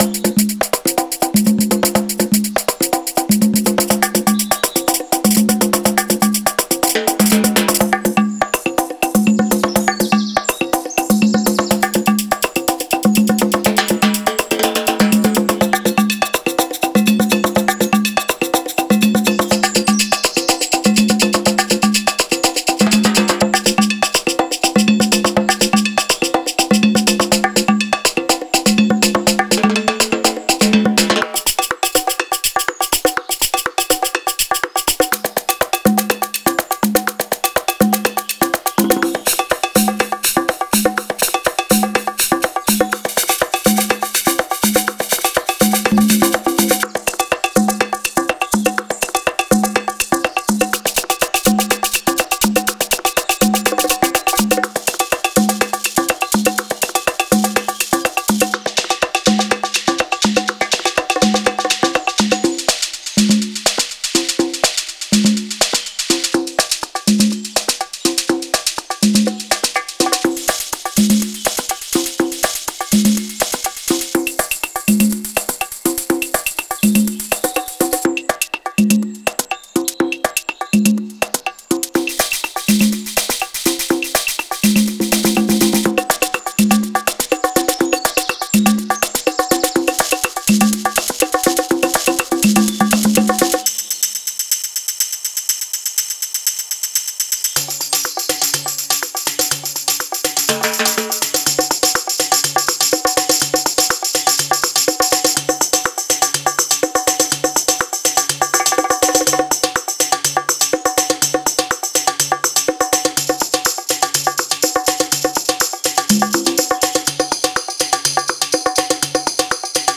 Genre:Tech House
微細な複雑さと抗えないグルーヴを兼ね備えたループが、楽曲に生命を吹き込みます。
デモサウンドはコチラ↓
60 Tambourine Loops 126 Bpm
60 Shakers Loops 126 Bpm